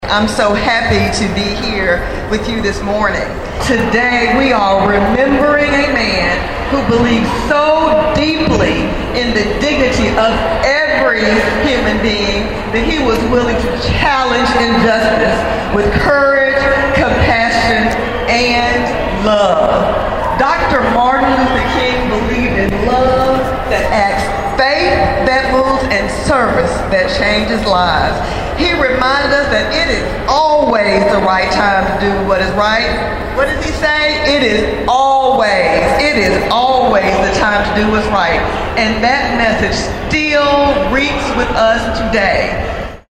A large crowd came to Discovery Park of America on Monday to take part in the annual celebration in remembrance of Dr. Martin Luther King Jr.